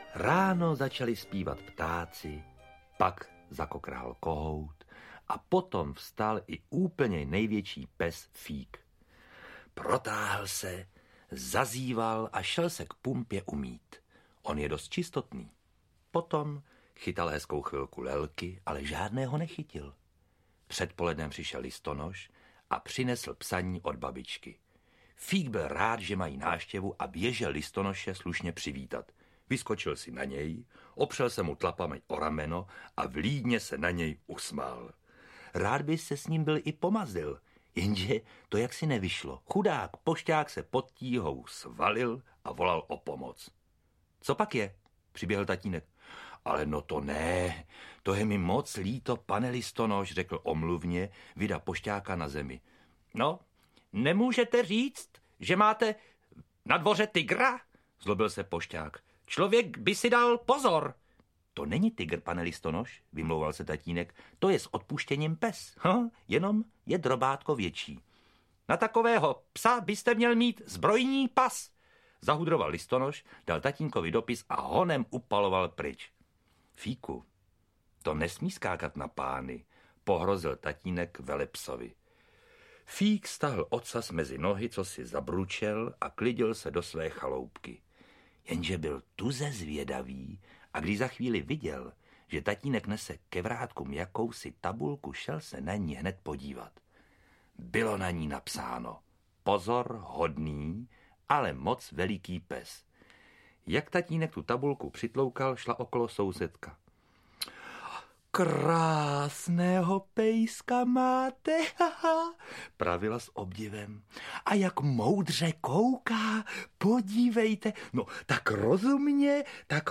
Maxipes Fík (komplet) audiokniha
Zvuková verze animovaného seriálu Maxipes Fík, který si získal nesmírnou oblibu u dětského publika.
Ukázka z knihy